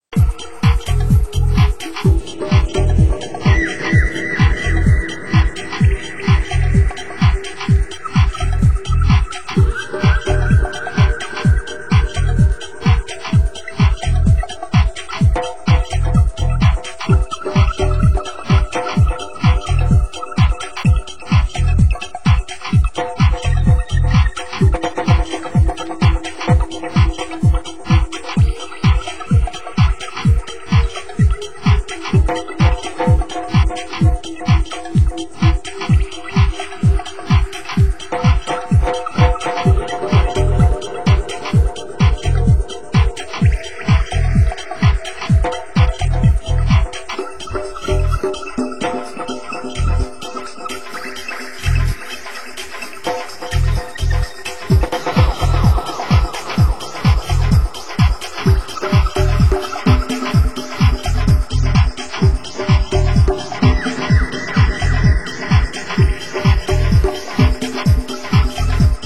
Genre: Nu Skool Breaks